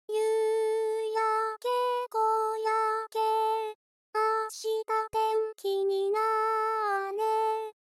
実際、上の「チャルメラ」も「夕焼け小焼け」も、「ド」「レ」「ミ」の三音でできていますが、どちらも真ん中の「レ」で終わっています。
歌声は、いずれも「初音ミク」を使用。）